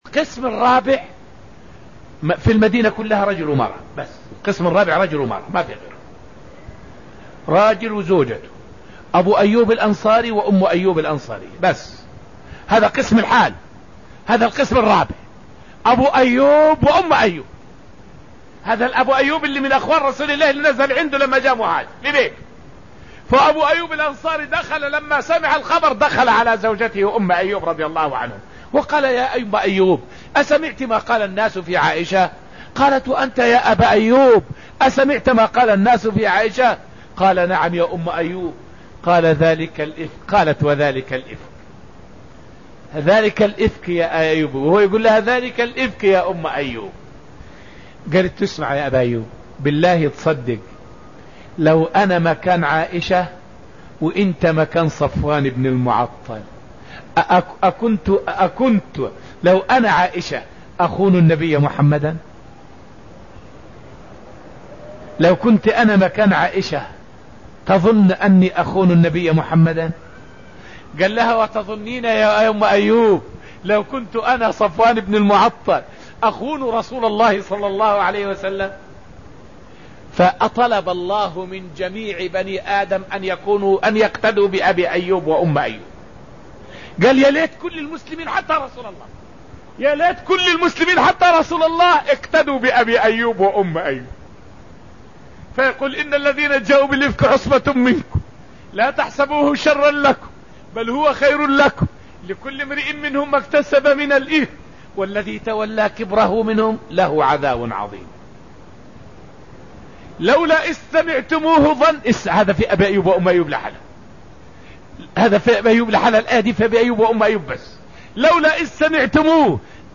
فائدة من الدرس الثامن من دروس تفسير سورة الحشر والتي ألقيت في المسجد النبوي الشريف حول موقف أبي أيوب الأنصاري وزوجه من حادثة الإفك.